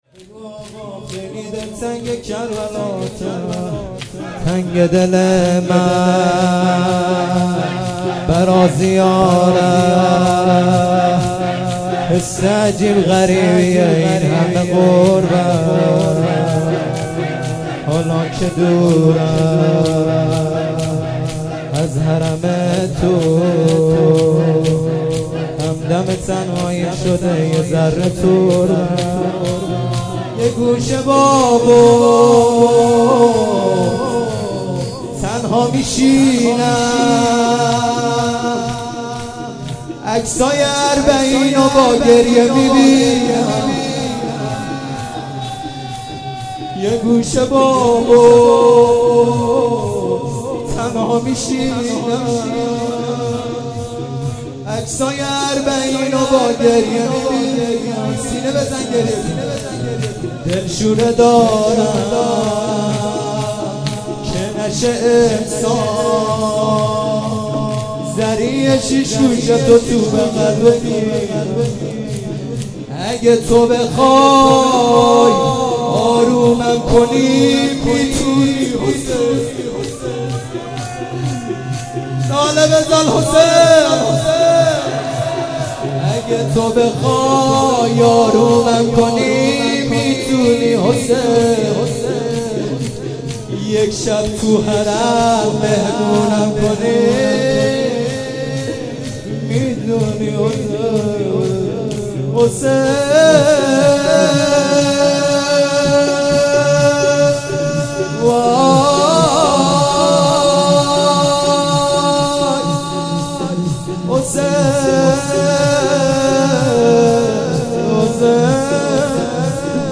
شور _تنگ دل من برا زیارت